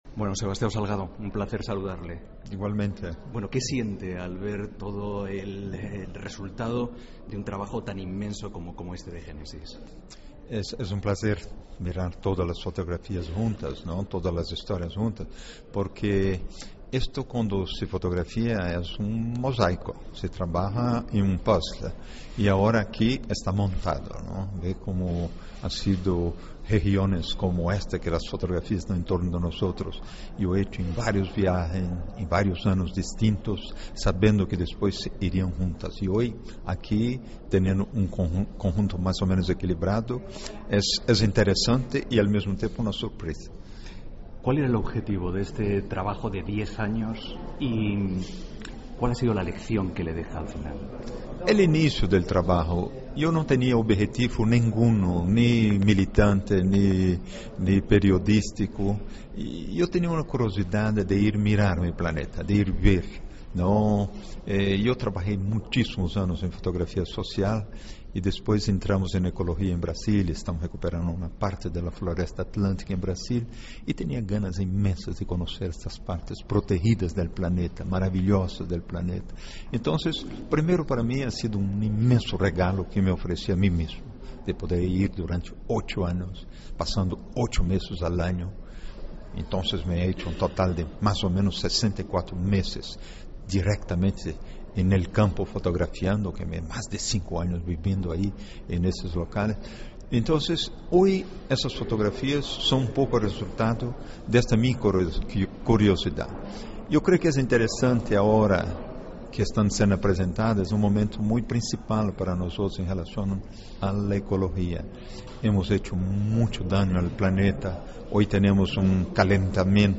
Escucha aquí la entrevista a Sebastiao Salgado